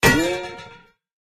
metal_hit_03.ogg